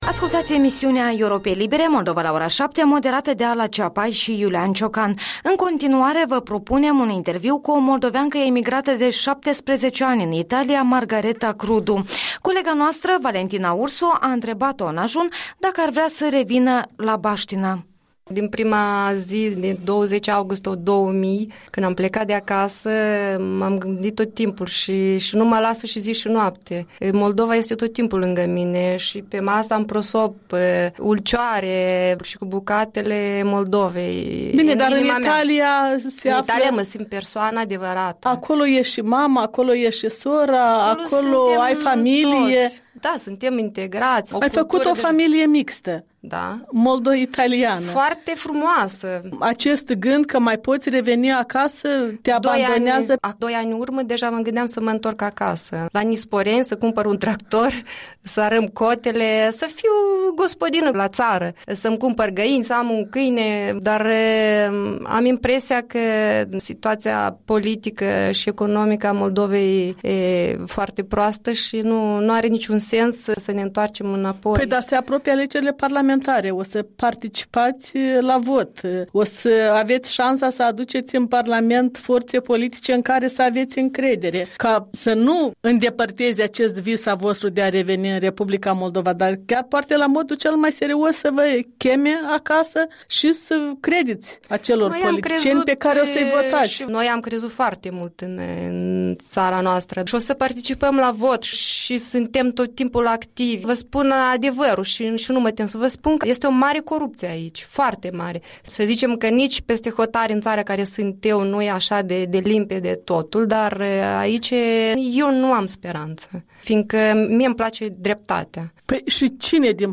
Interviul dimineții cu o moldoveancă emigrată în urmă cu 17 ani în Italia.